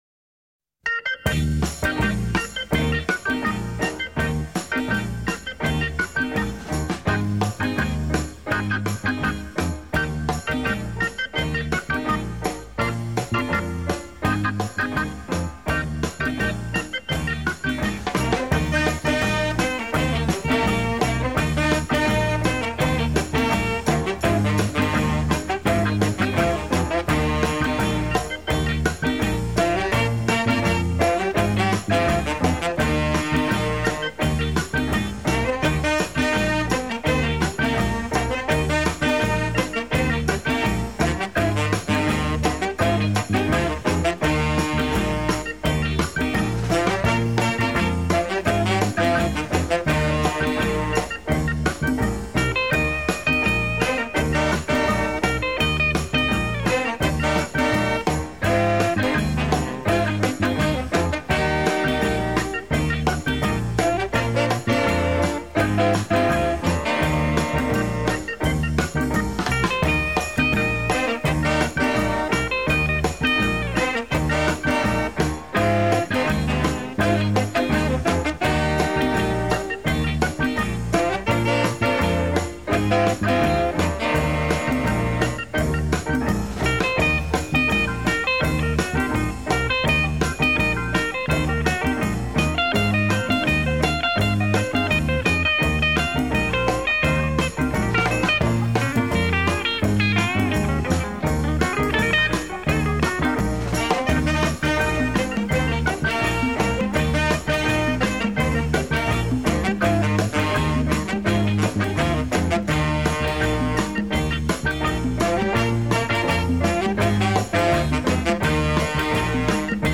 Модерн-бит-